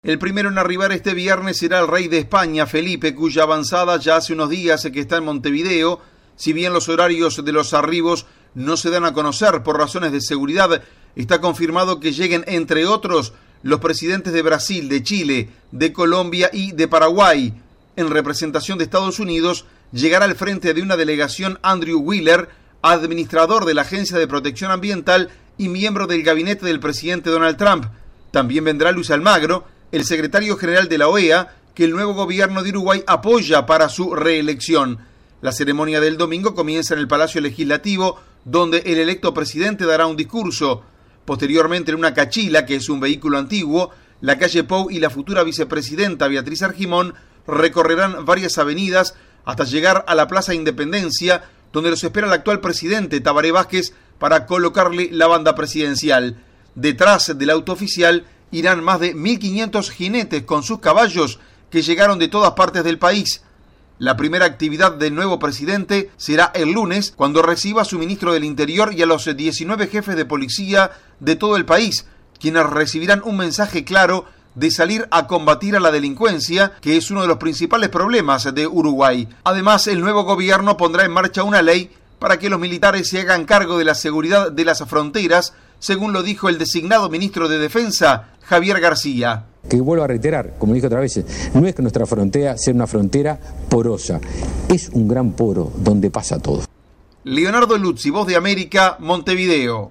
VOA: Informe de Uruguay